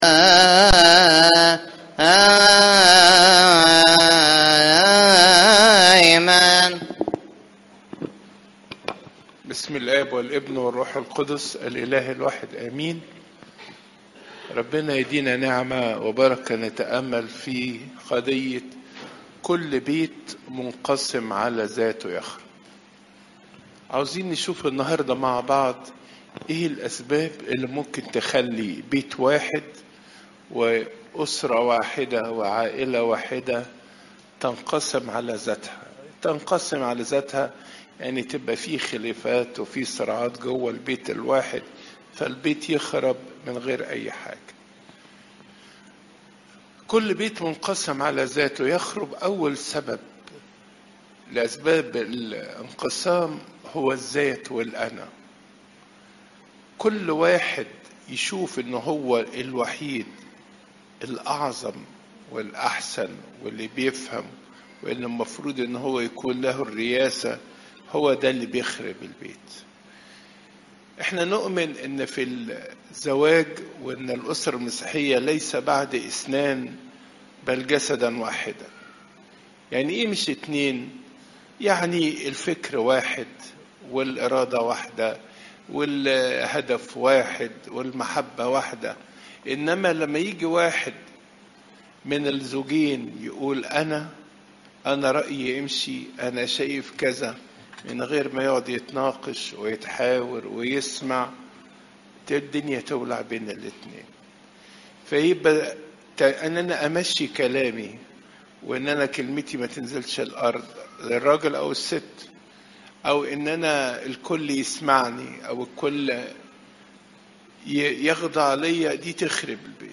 عظات قداسات الكنيسة (مر 3 : 22 - 35)